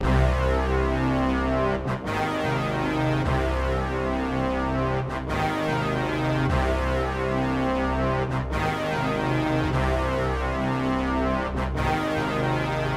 发条式低黄铜
描述：低铜管和合成器
Tag: 148 bpm Hip Hop Loops Brass Loops 2.18 MB wav Key : Unknown